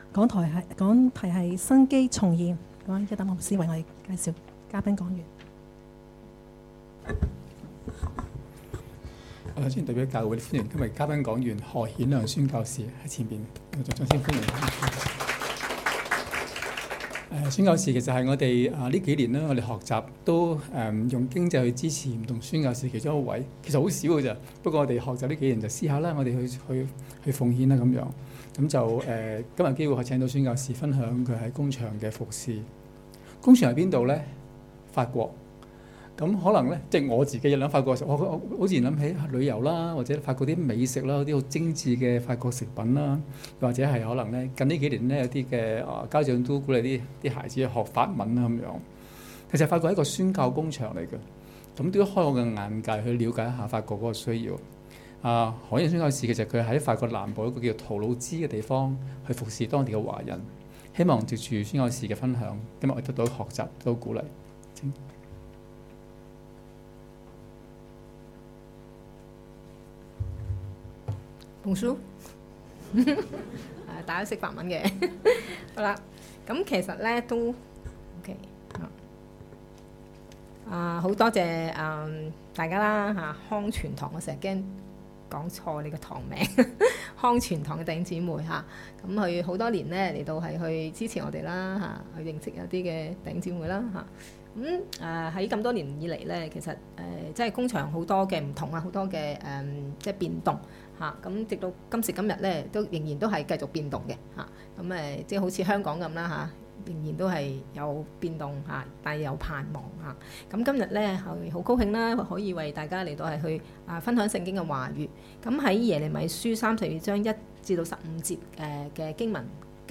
2019年8月17日及18日崇拜